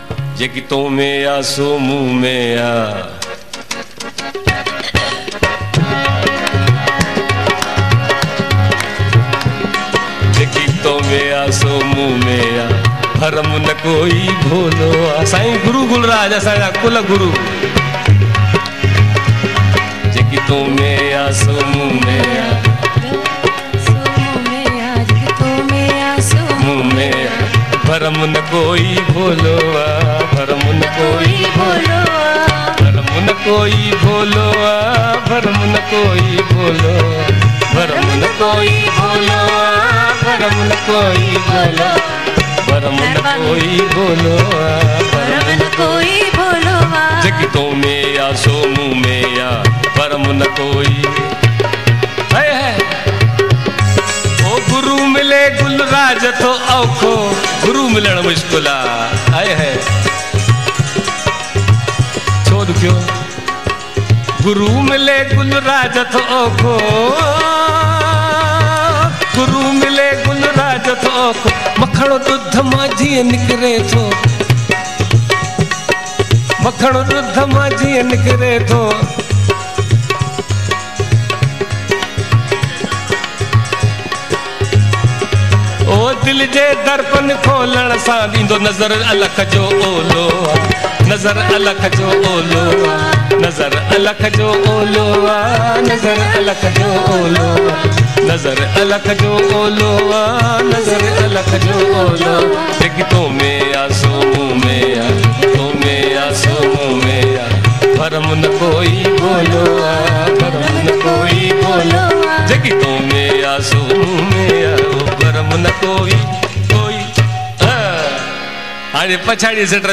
Format: LIVE
Live Performance